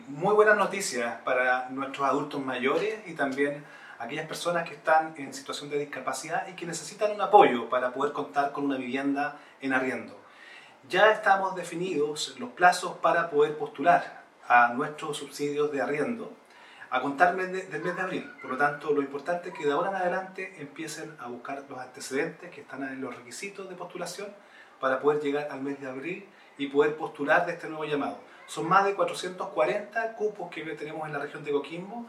Angelo-Montano-Director-Regional-de-SERVIU.mp3